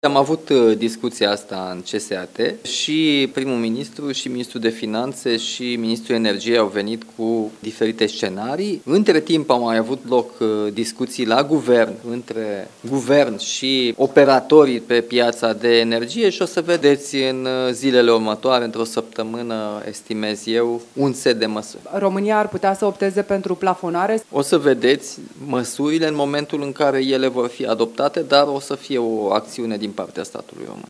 De la Bruxelles, unde participă la reuniunea Consiliului European, președintele României, Nicușor Dan, anunță că, într-o săptămână, statul român va interveni pe piața de energie, pentru ca populația să suporte mai ușor scumpirea carburanților din ultima perioadă.